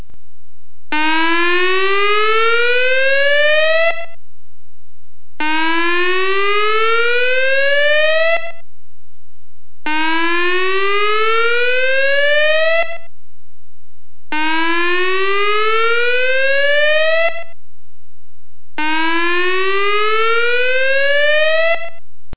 Splityard Creek Dam emergency sirens | CleanCo Queensland
The siren will be activated as a Standard Emergency Warning Signal.
4_WHOOP.mp3